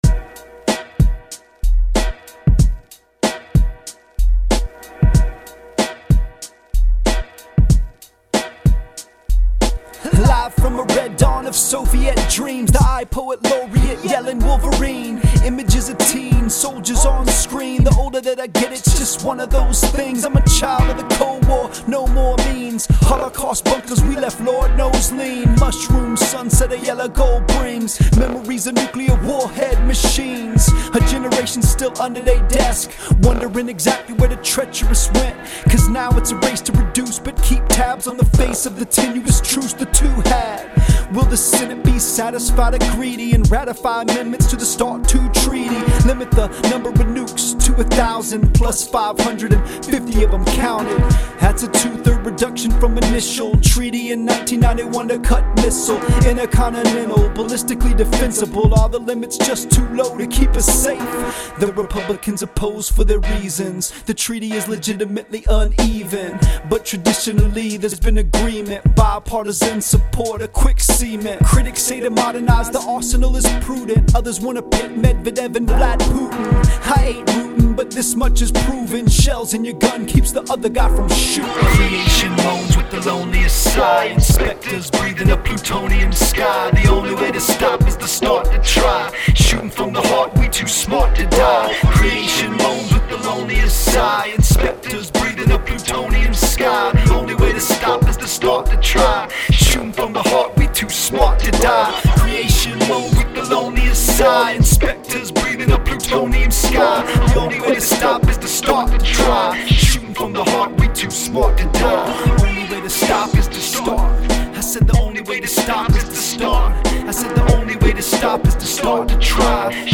A throwback Monday song.